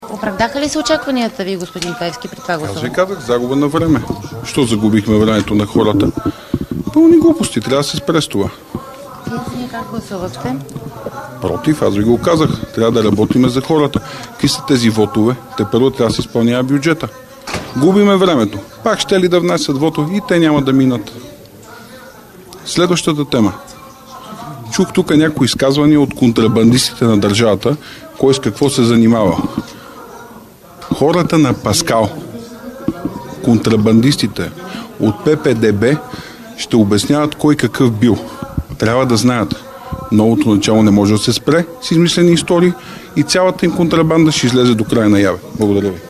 10.50 - Брифинг на председателя на „Възраждане" Костадин Костадинов. директно от мястото на събитието (пл. „Княз Александър I" №1)
Директно от мястото на събитието